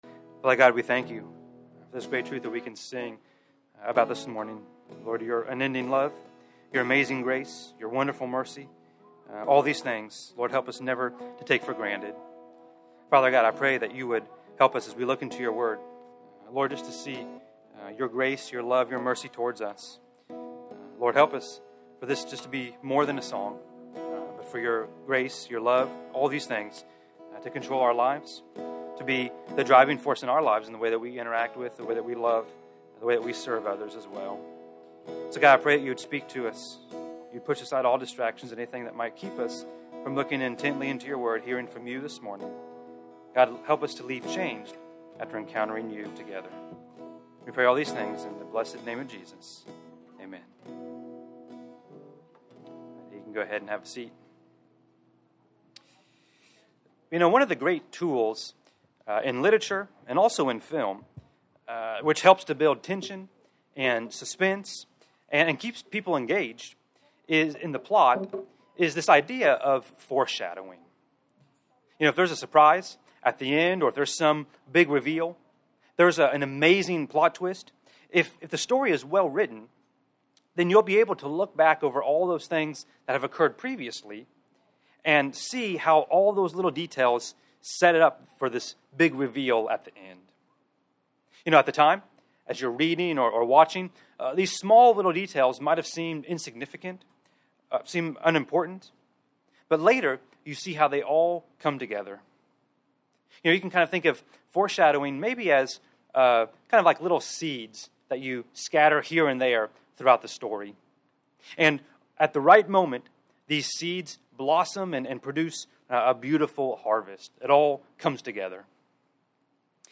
From Series: "English Sermons"